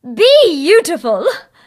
bea_kill_vo_04.ogg